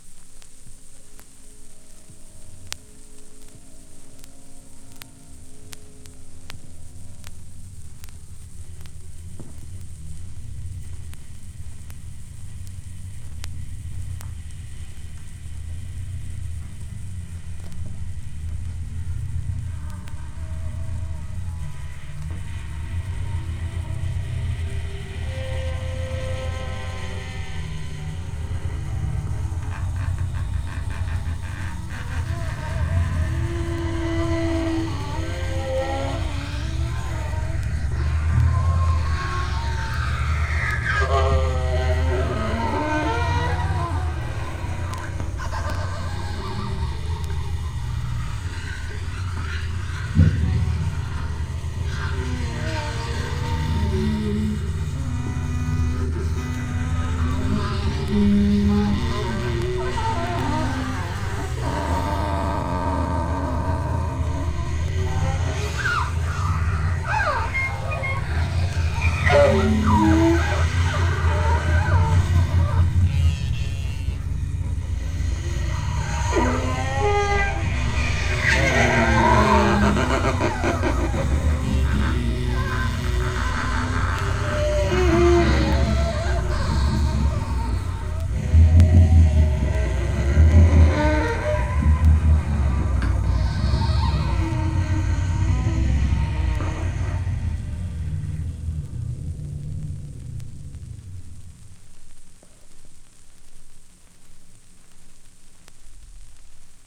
Vinyl digitalisiert mit:
1A1 01 bow on bow sextet, turn table (16 Kanal stereo) 17.03